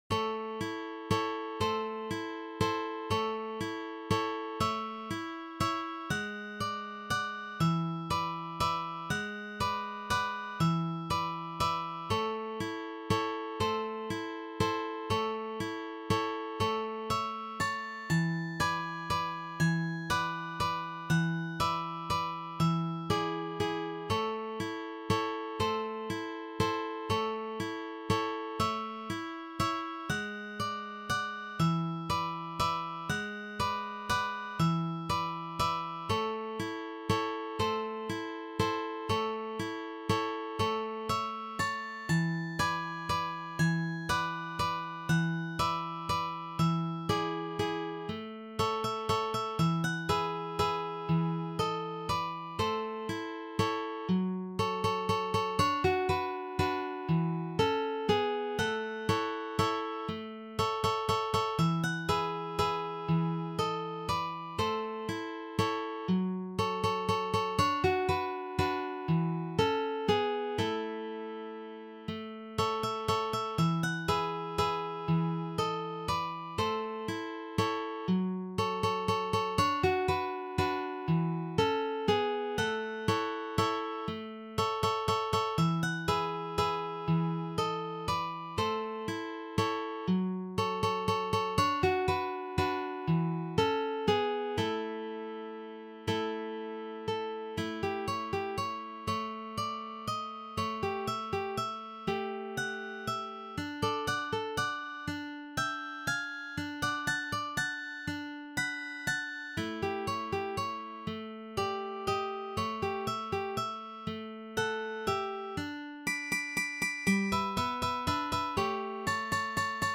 arranged for three guitars